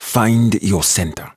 召唤少林寺武僧攻击敌人，语音多与武术有关并带有严重的口音。